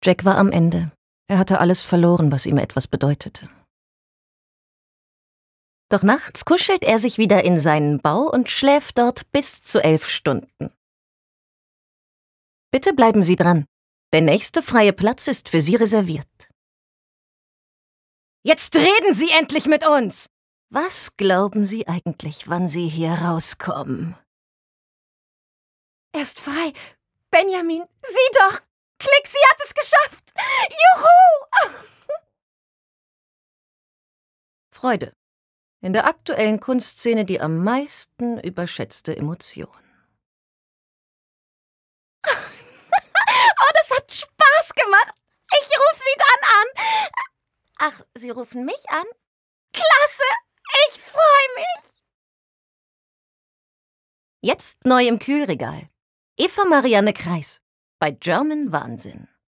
Schauspielerin und Sprecherin
Kein Dialekt
Sprechprobe: Werbung (Muttersprache):